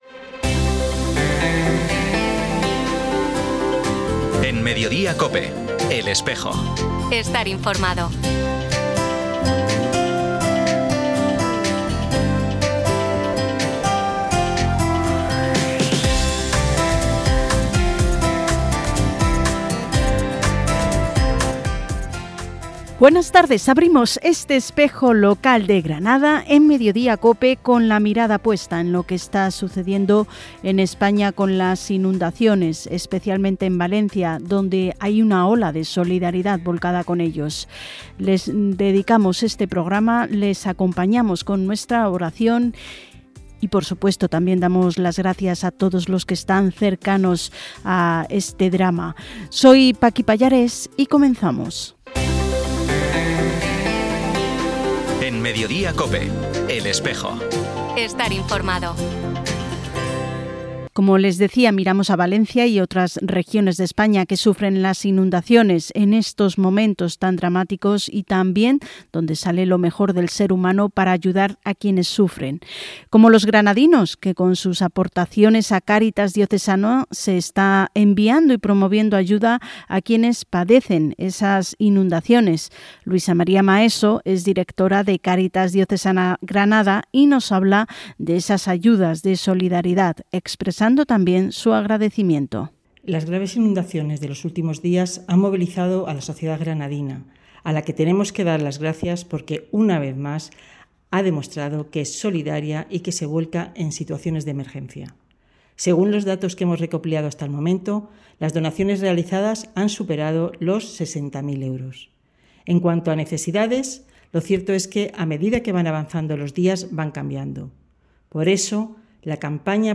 Programa emitido en COPE Granada y COPE Motril el 8 de noviembre.